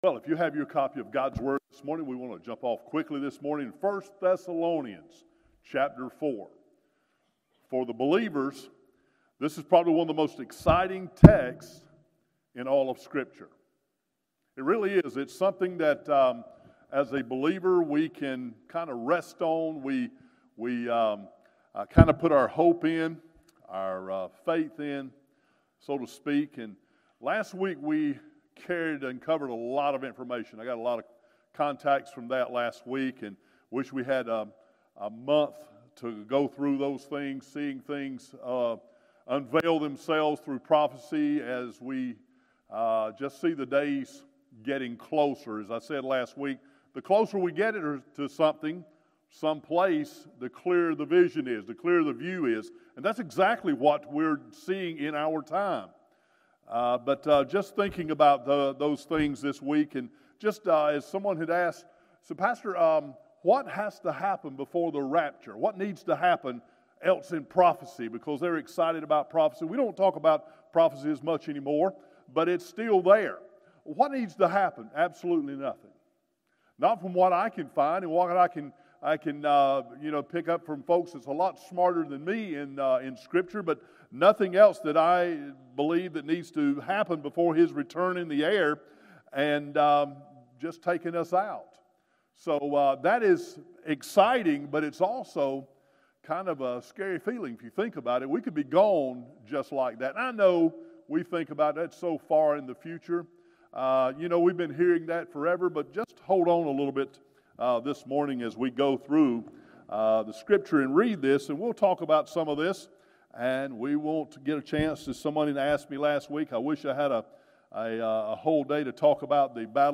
Morning Worship - 11am Passage